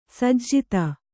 ♪ sajjita